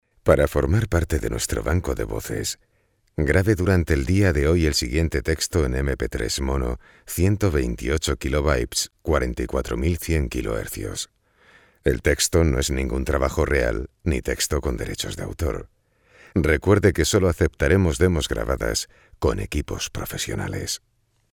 The studio features expert acoustic design and the latest in digital audio technology like : Microphones:  Kahayan 4k7 . Neumann TLM 170. Tube Tech equalizer, Apogee Symphony...  Quality and the better sound.